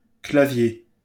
Clavier (French pronunciation: [klavje]